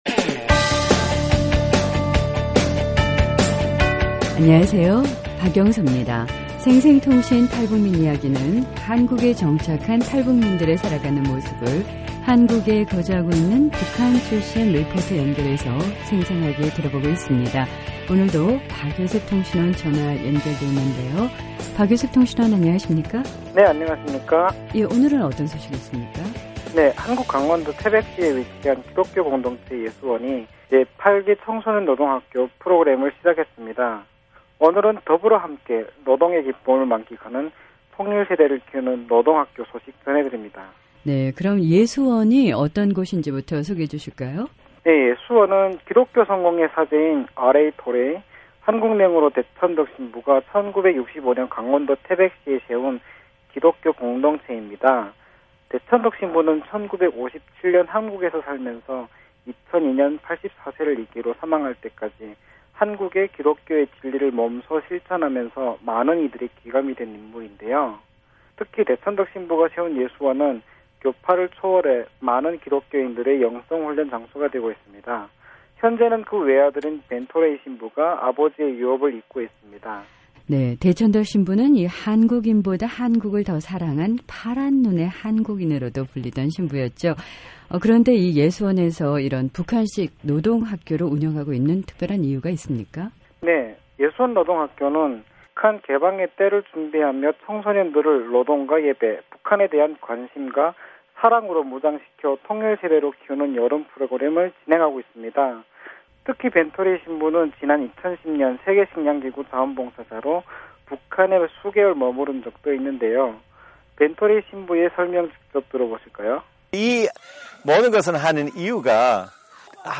생생통신 탈북민이야기는 한국에 정착한 탈북민들의 살아가는 모습을 한국에 거주하고있는 북한출신리포터 연결해 생생하게 들어보는 시간입니다. 한국 강원도 태백시에 위치한 기독교 공동체 예수원이 제 8기 청소년 노동학교 프로그램을 시작했습니다. 오늘은 “ 더불어 함께 노동의 기쁨을 만끽하는 통일 세대”를 키우는 노동학교 소식 전해드립니다.